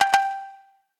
shamisen_gg.ogg